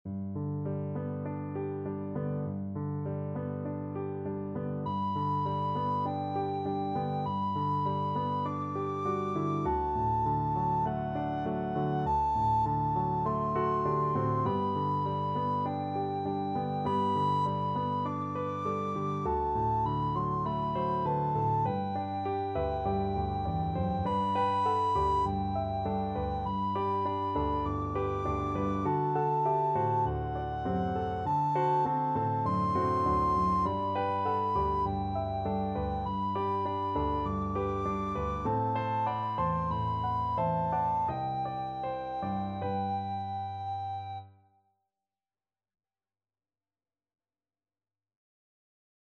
Traditional Trad. Skip to my lou Soprano (Descant) Recorder version
4/4 (View more 4/4 Music)
G major (Sounding Pitch) (View more G major Music for Recorder )
Gently Flowing = c.100
Traditional (View more Traditional Recorder Music)